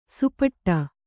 ♪ supaṭṭa